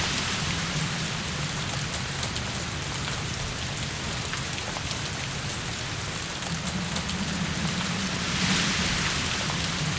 Bruitage – Jour de pluie – Le Studio JeeeP Prod
Bruitage haute qualité créé au Studio.